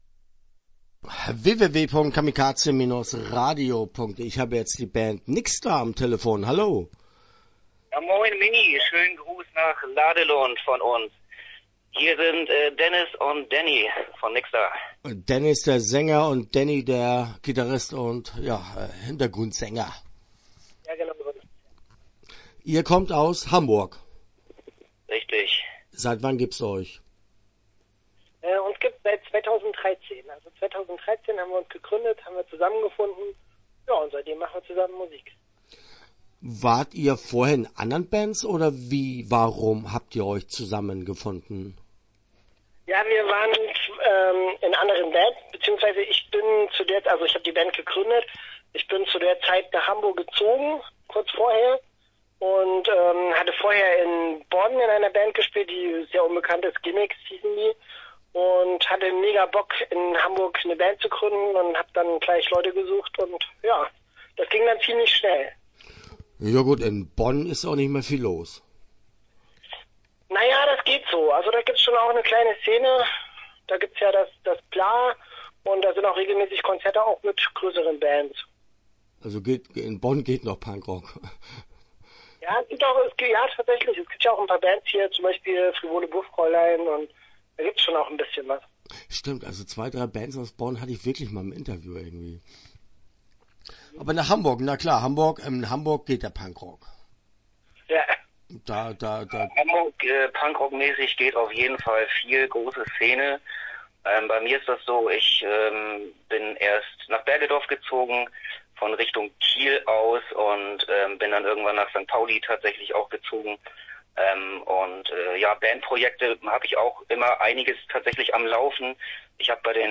- Interview Teil 1 (11:41)